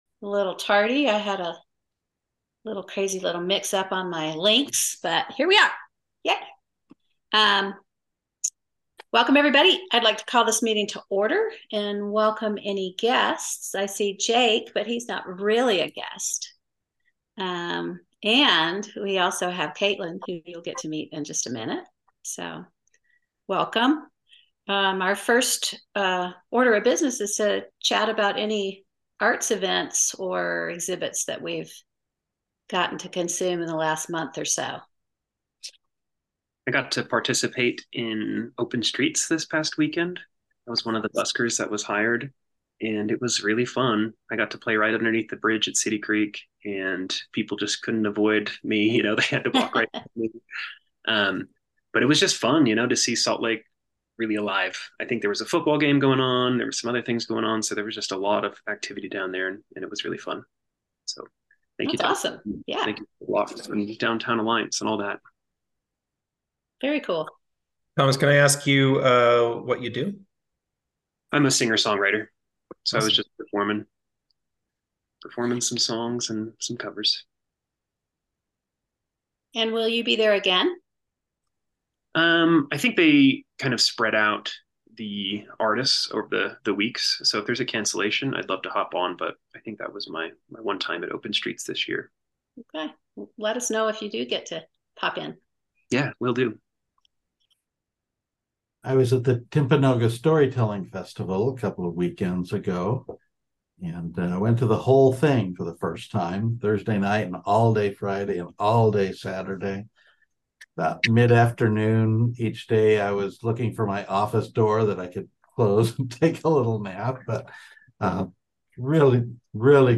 Art Council Board Meeting 9.20.23